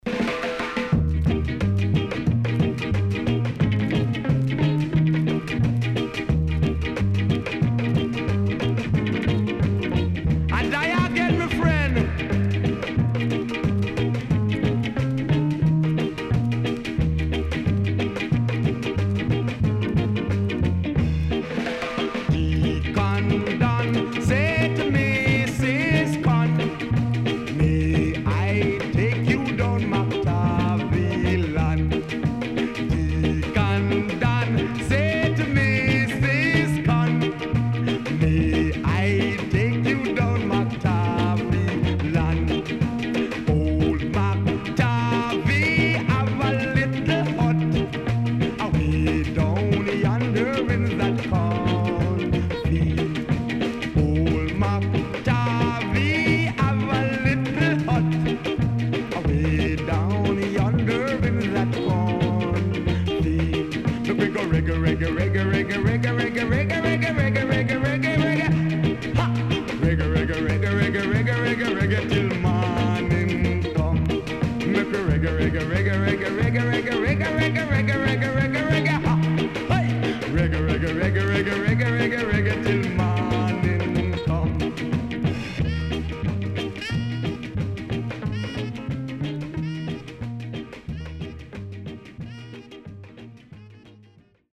SIDE A:序盤に目立つプチノイズ入ります。最終にも少しプチノイズ入ります。